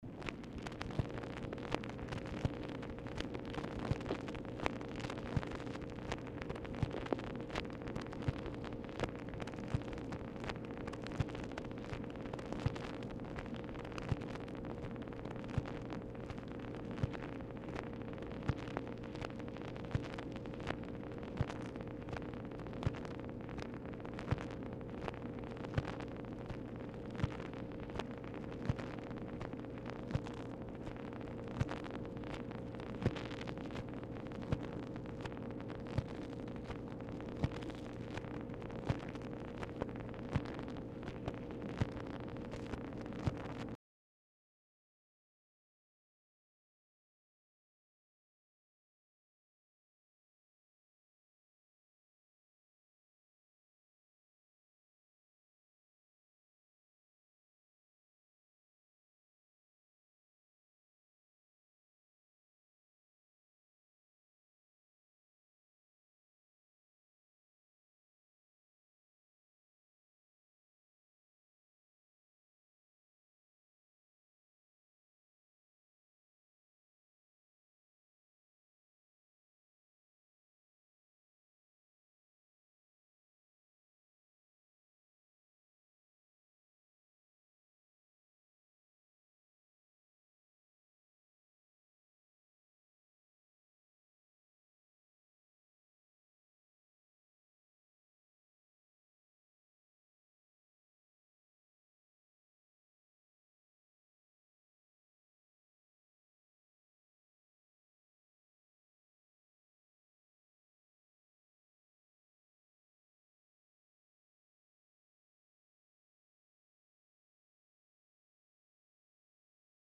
MACHINE NOISE
Mansion, White House, Washington, DC
Telephone conversation
Dictation belt